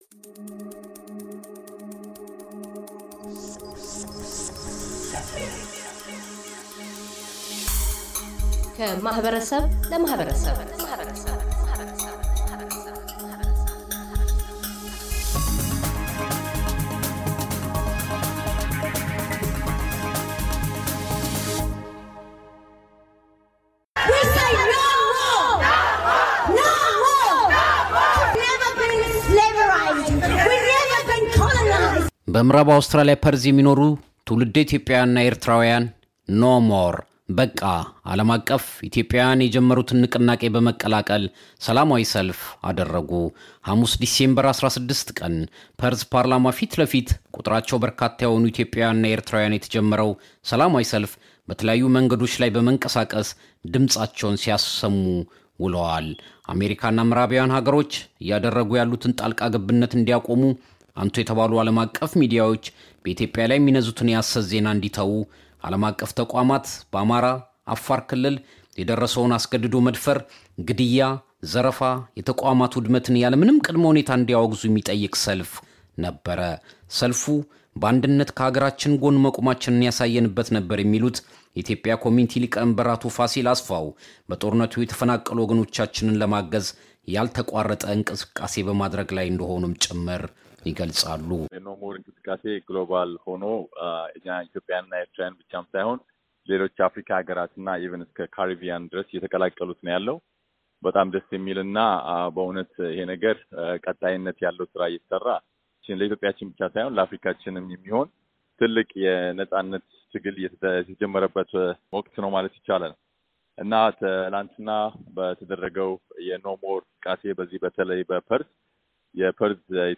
በፐርዝ-ምዕራብ አውስትራሊያ ነዋሪ የሆኑ ትውልደ-ኢትዮጵያውያንና ኤርትራውያን የዓለም አቀፉ "በቃ" ንቅናቄ አክል የሆነ የተቃውሞና ድጋፍ ሰልፍ አካሂደዋል። የሰልፉን ዓላማና ፋይዳ አስመልክቶ የሁለቱ ማኅበረሰባት መሪዎች ይናገራሉ።